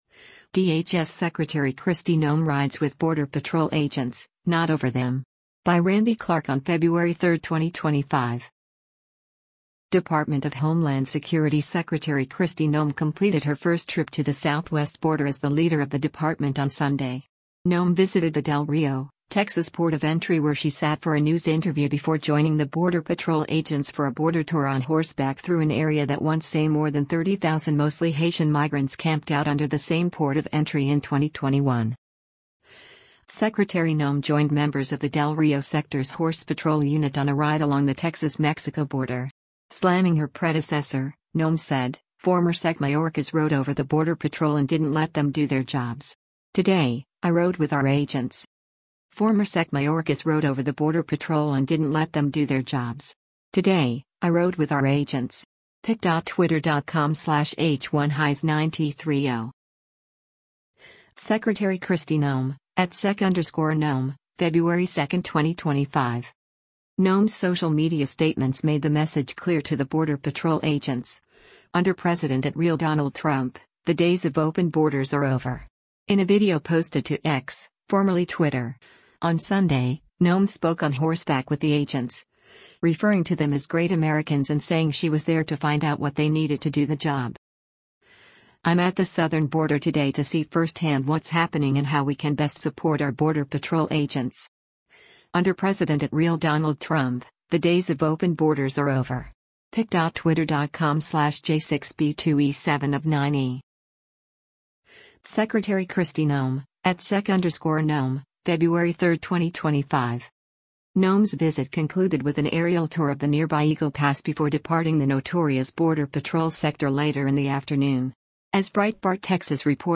DHC Secretary Kristi Noem rides the border with Del Rio Sector Agents (Department of Homel
In a video posted to X, formerly Twitter, on Sunday, Noem spoke on horseback with the agents, referring to them as “great Americans” and saying she was there to find out what they needed to do the job.